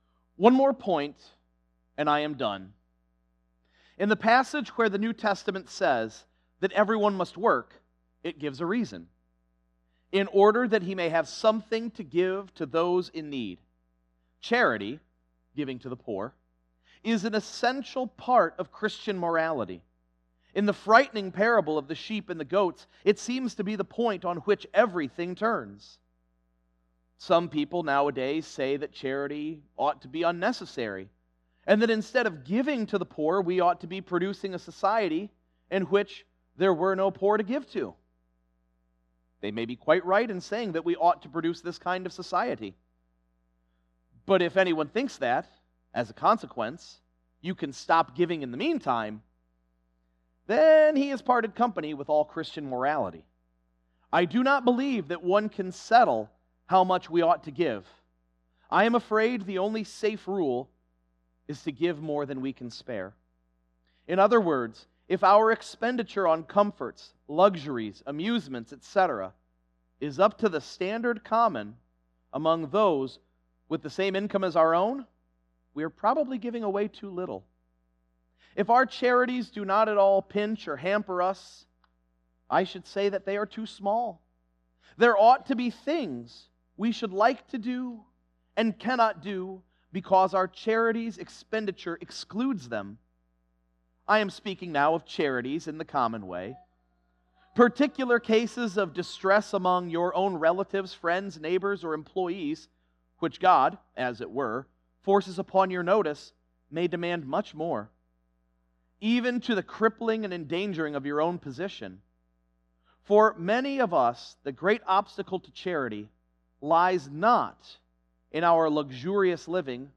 Sermons Archive